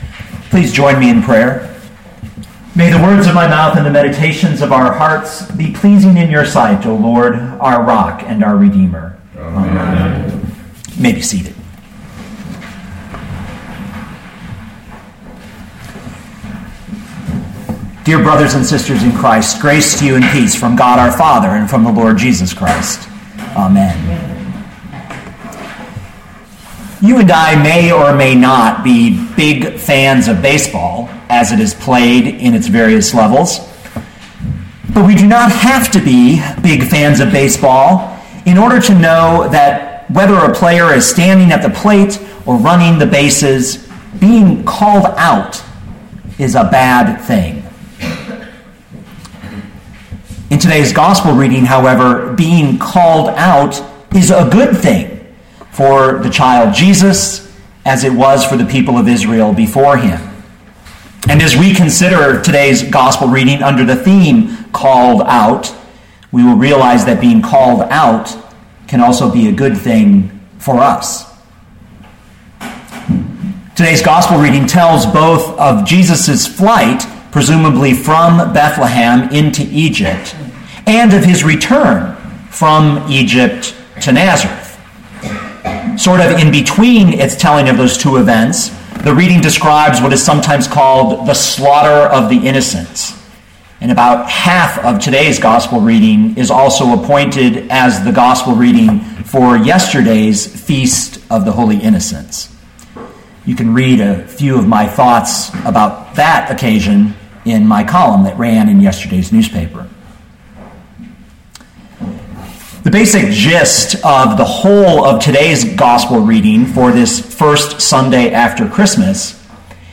2013 Matthew 2:13-23 Listen to the sermon with the player below, or, download the audio.